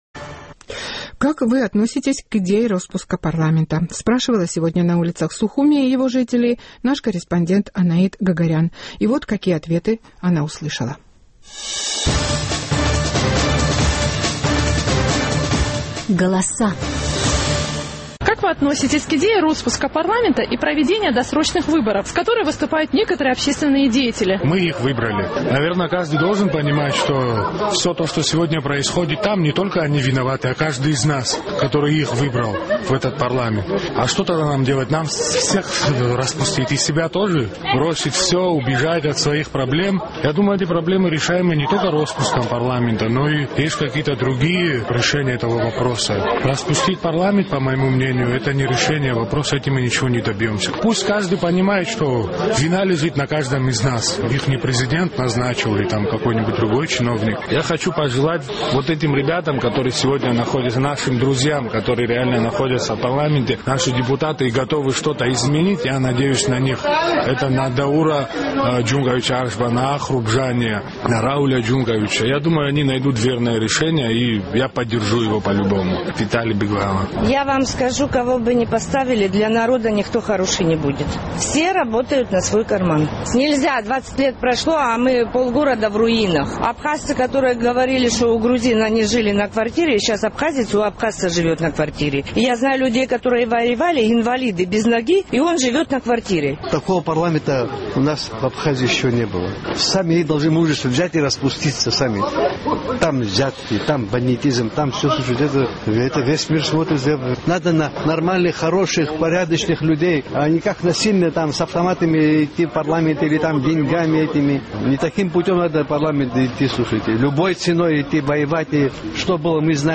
Как вы относитесь к идее роспуска парламента и проведения досрочных выборов – спрашивала сегодня на улицах абхазской столицы ее жителей наш сухумский корреспондент.